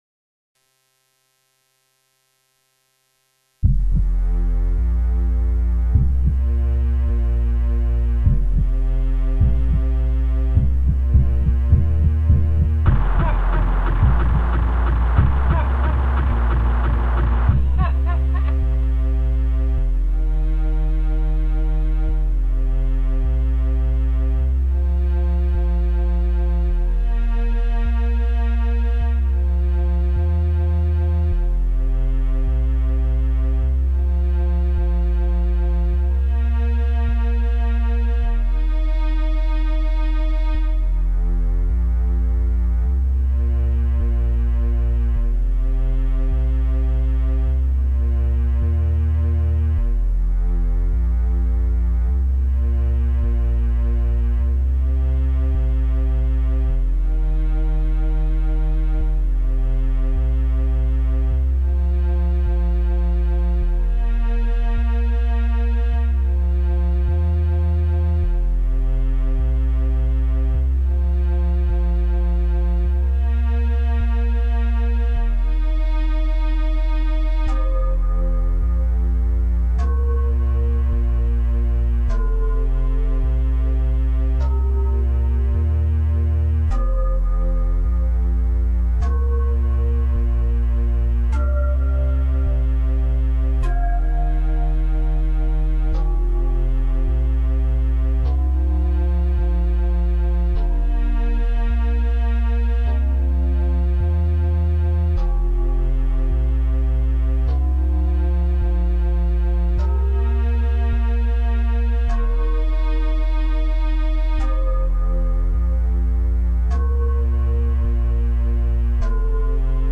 This has pretty bad sound quality, but this is the best I could upload due to filesize limitations...